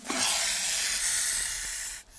hiss.wav